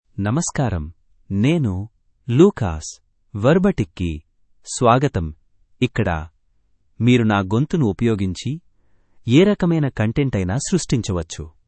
MaleTelugu (India)
Lucas — Male Telugu AI voice
Voice sample
Male
Lucas delivers clear pronunciation with authentic India Telugu intonation, making your content sound professionally produced.